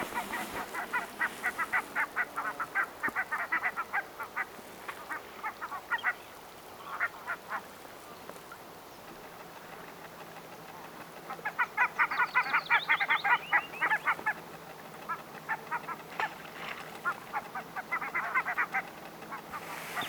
valkoposkihanhipari ääntelee